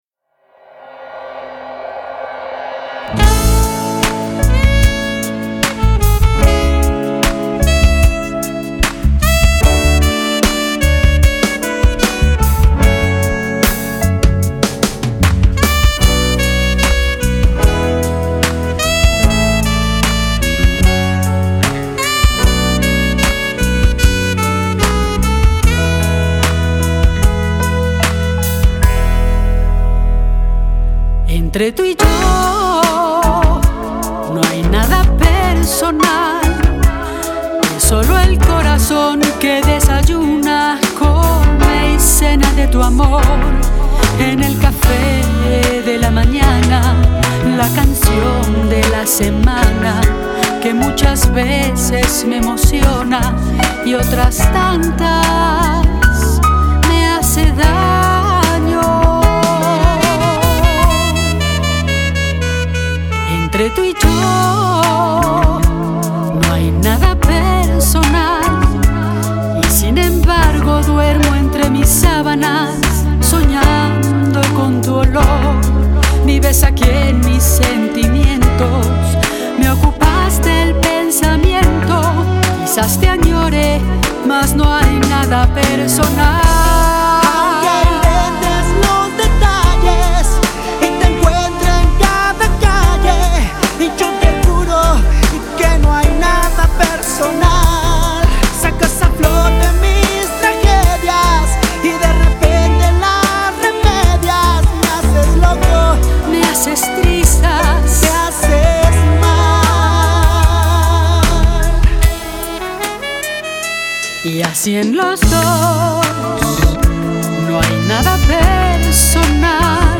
Género: Balada Pop.